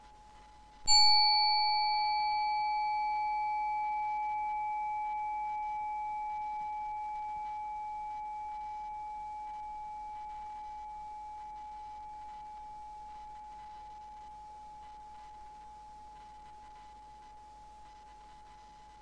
Solfeggio能量棒风铃 " EBC852solo08bpur
Tag: 心清 冥想 852赫兹 视唱练耳能量棒 钟声 放松声音 有毒性无-PUR 精神阶 能源 陈建声音 视唱练耳频率